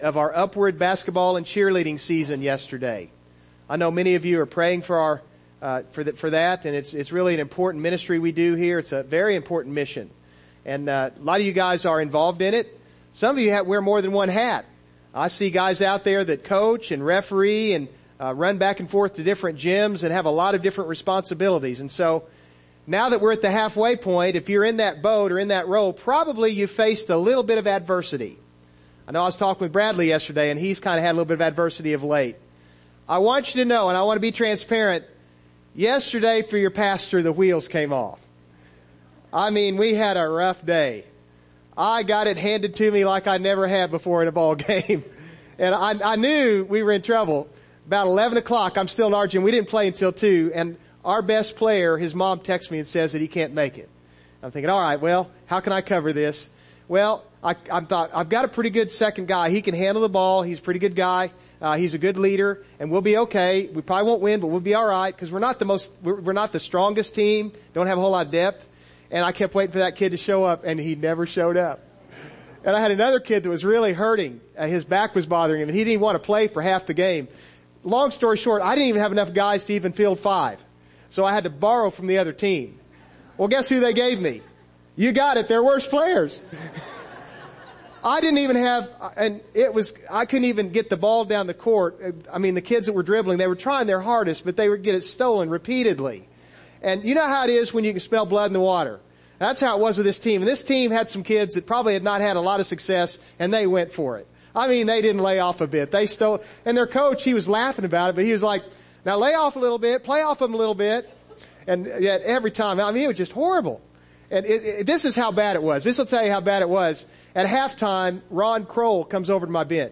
Passage: 1 Corinthians 12:12-20, 27 Service Type: Morning Service